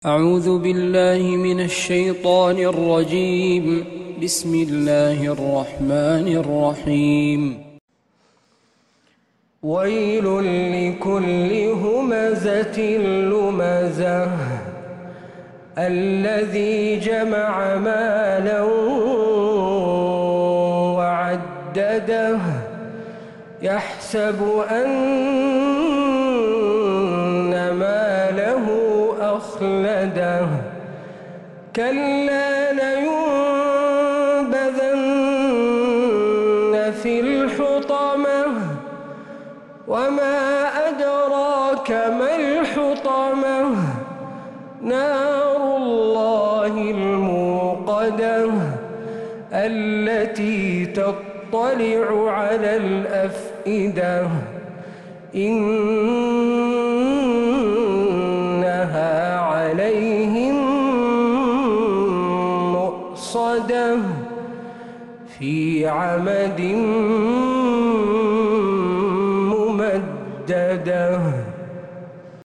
من مغربيات الحرم النبوي